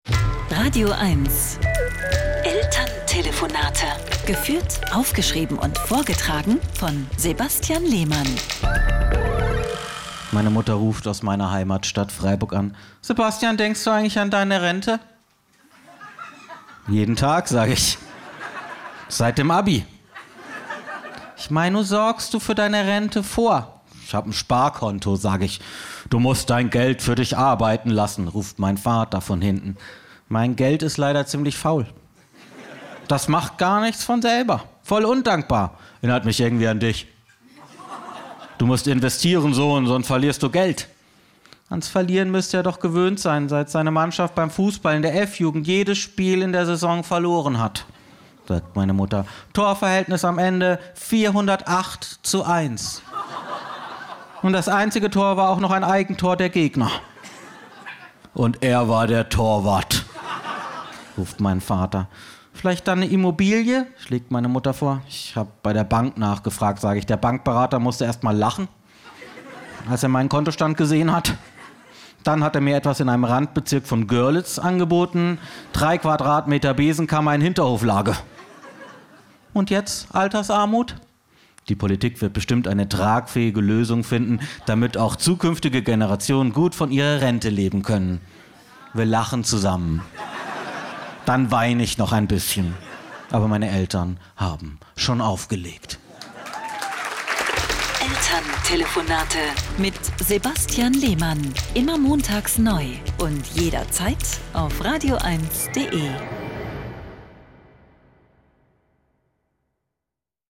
Deswegen muss er oft mit seinen Eltern in der badischen Provinz telefonieren.
Comedy